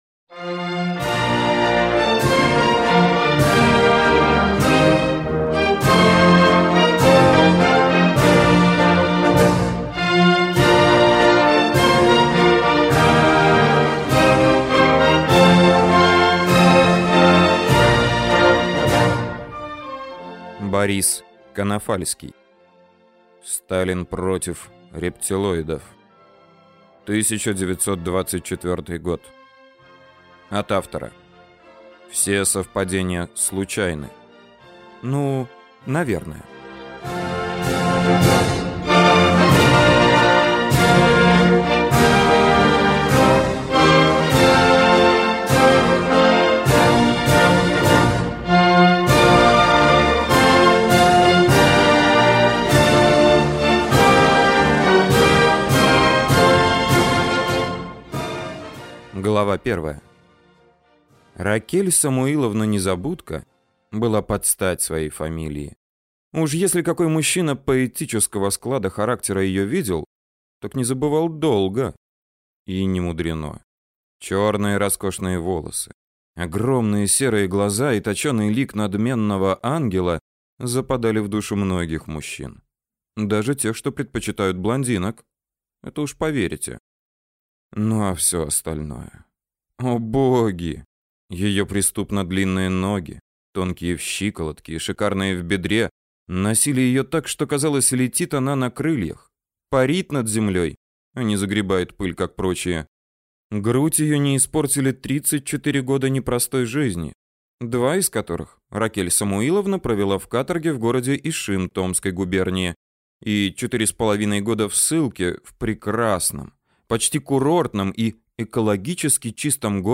Аудиокнига Сталин против рептилоидов | Библиотека аудиокниг